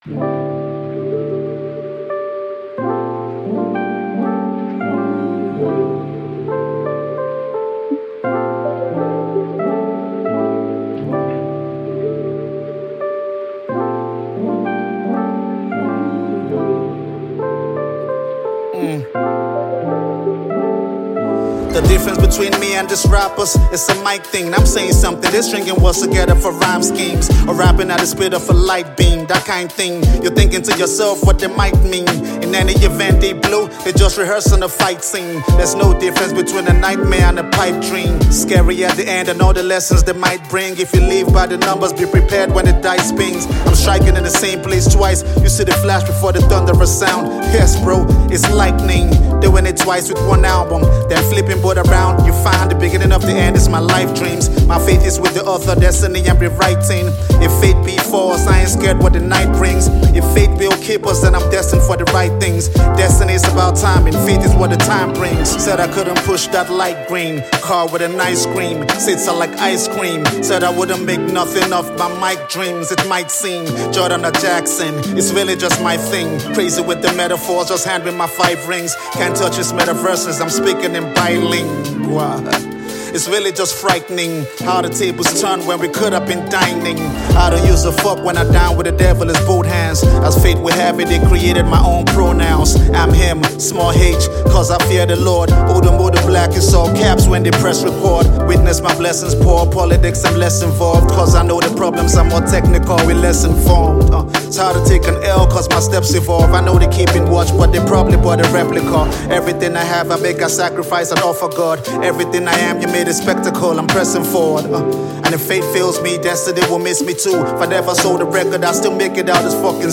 studio project